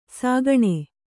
♪ sāgaṇe